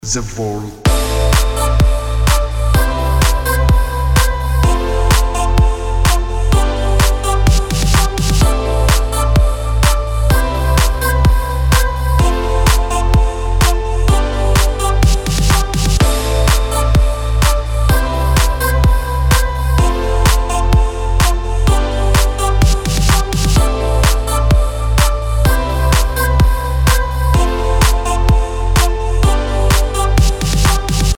• Качество: 256, Stereo
громкие
красивые
deep house
без слов
Красивая и звонкая мелодия